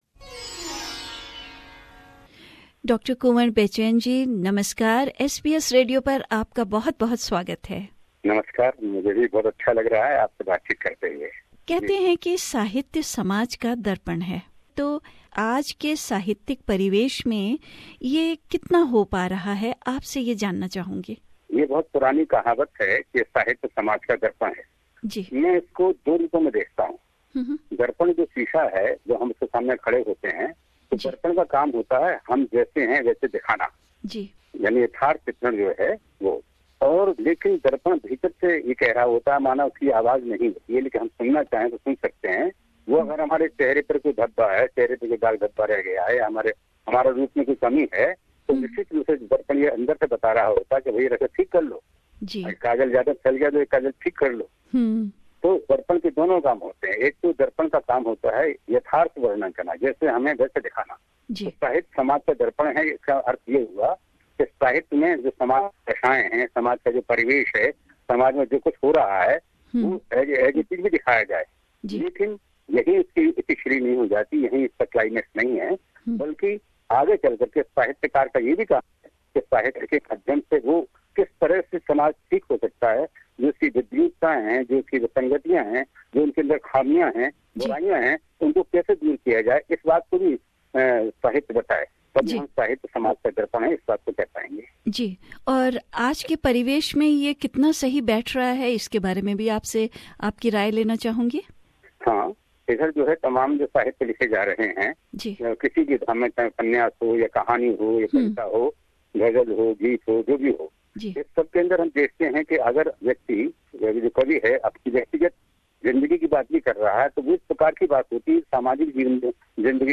An Interview with Poet Dr. Kunwar Bechain | SBS Hindi